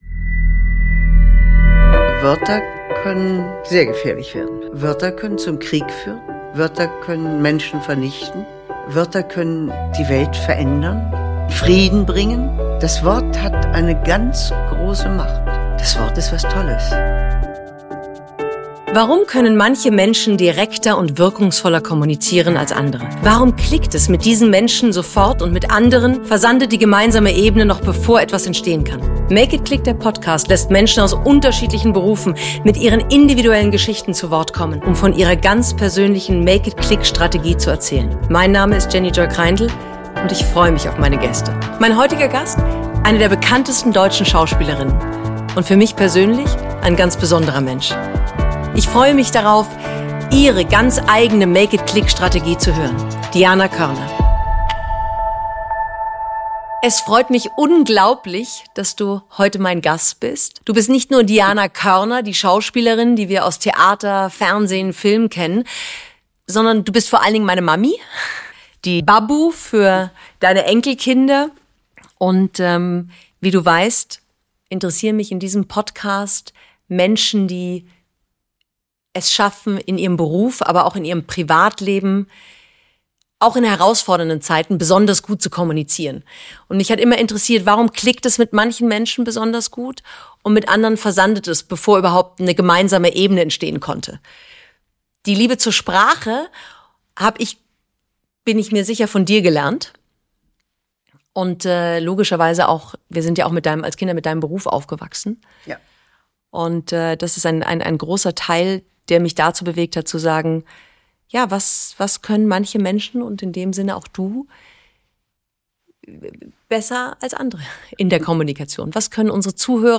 Die Schauspielerin spricht über ihre ganz persönliche Art zu kommunizieren, erzählt, was Menschen für sie zu attraktiven Kommunikatoren macht und was sie jedem raten würde, wenn es darum geht, es CLICKEN zu lassen.